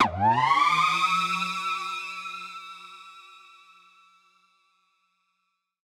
Index of /musicradar/future-rave-samples/Siren-Horn Type Hits/Ramp Up
FR_SirHornD[up]-E.wav